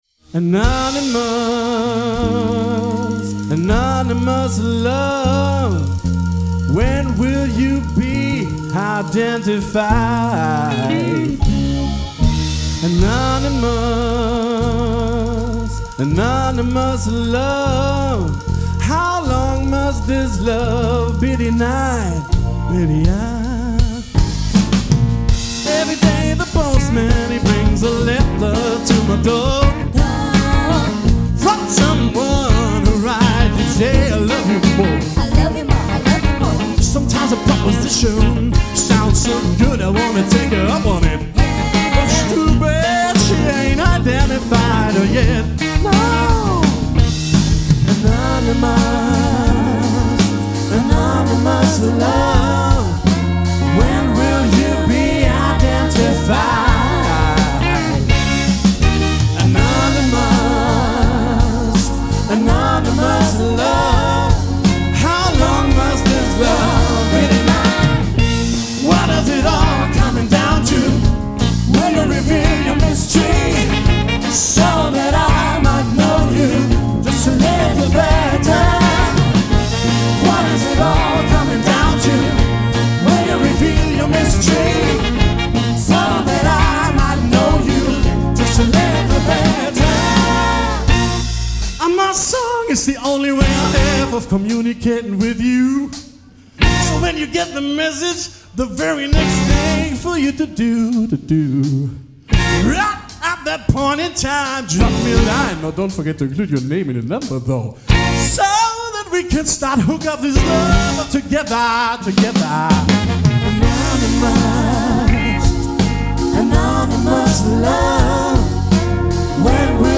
recorded live in Braunschweig am 13.4.2017